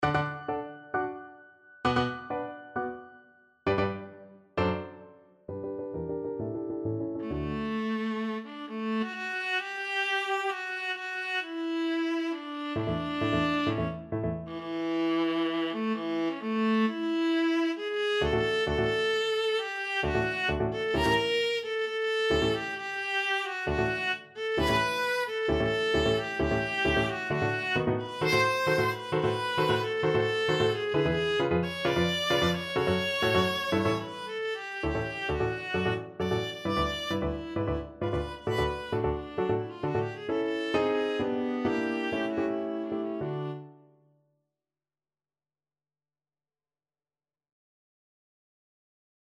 2/2 (View more 2/2 Music)
Viola  (View more Intermediate Viola Music)
Classical (View more Classical Viola Music)